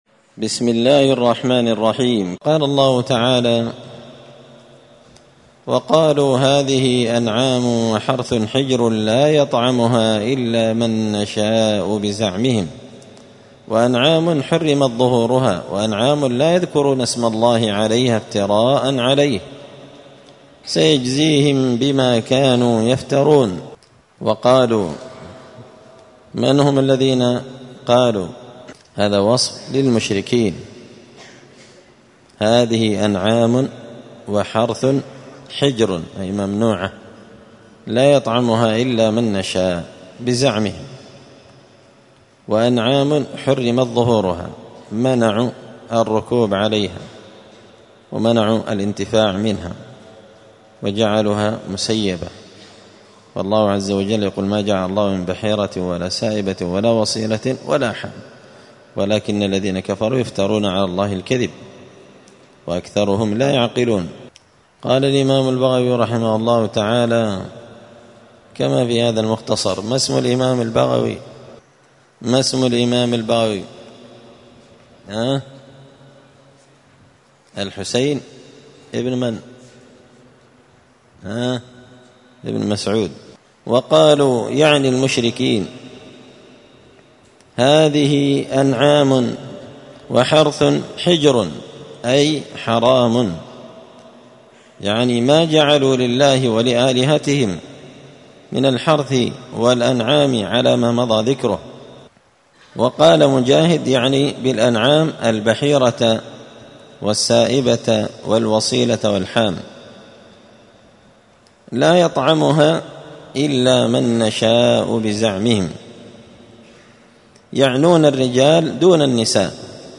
📌الدروس اليومية
مسجد الفرقان قشن_المهرة_اليمن